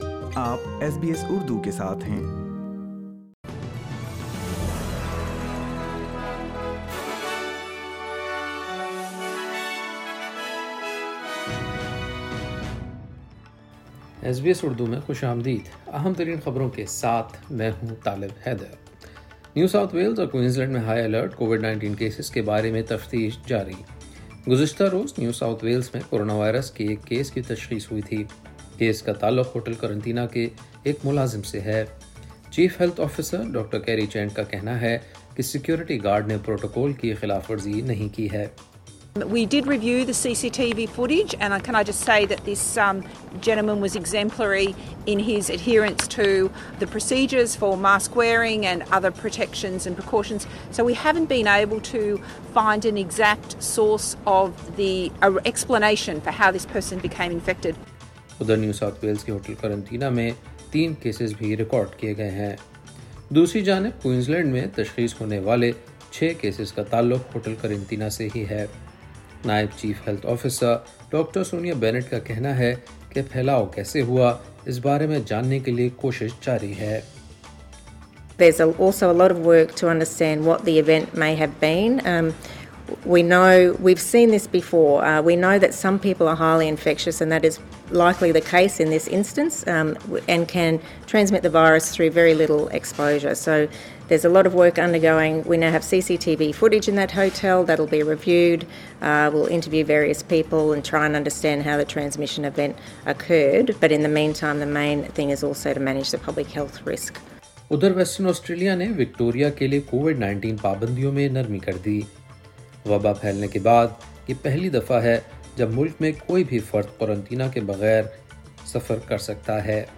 ایس بی ایس اردو خبریں 15 مارچ 2021